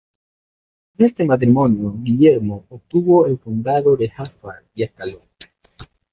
Gui‧ller‧mo
/ɡiˈʝeɾmo/